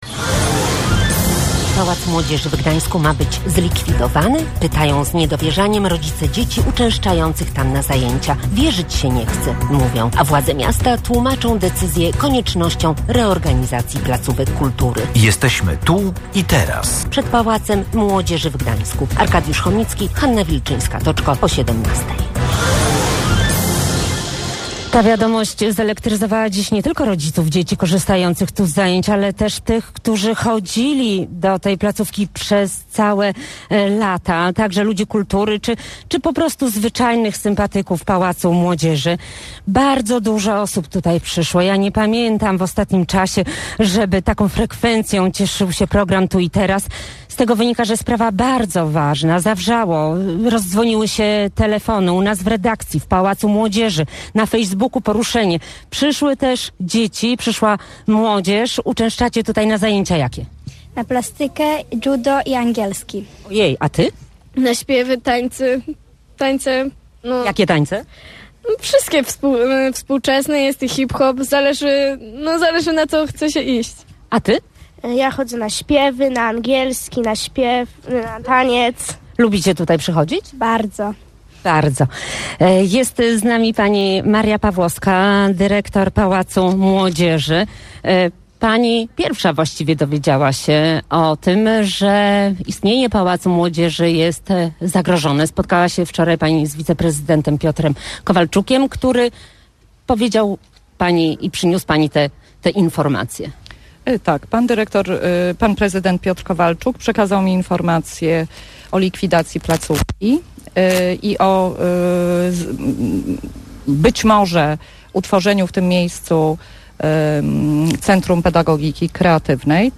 W środę o godz. 17:00 wóz satelitarny Radia Gdańsk zaparkował przed Pałacem Młodzieży w Gdańsku. Przyszli rodzice, uczestnicy zajęć, nauczyciele i przedstawiciele Urzędu Miasta.